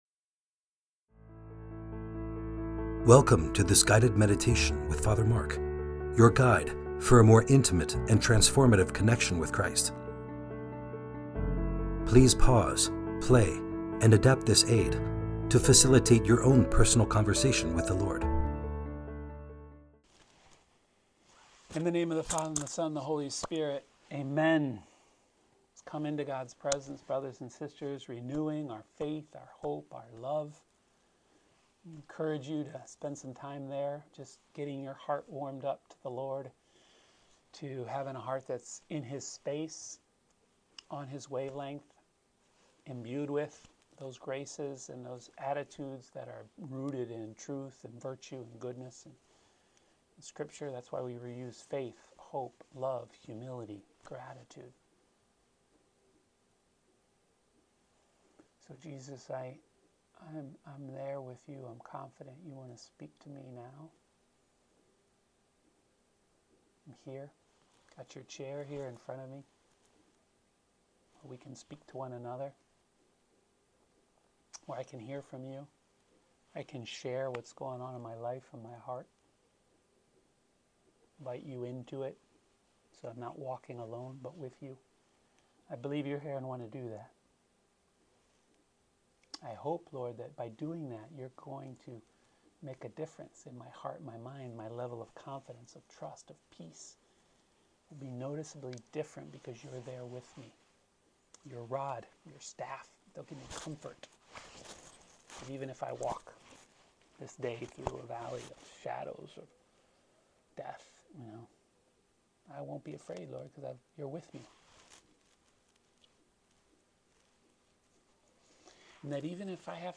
Luke 14: 1-6 - RC NY Tri-State
Due to technical issues, the full audio of this video is unavailable.